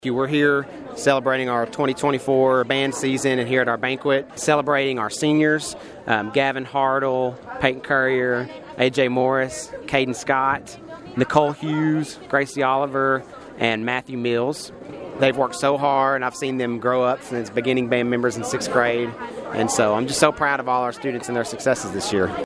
The Caldwell County High School Band of Pride held the end of the Band Banquet Friday night beginning in the high school cafeteria.